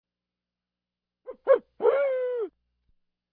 dog_yes.ra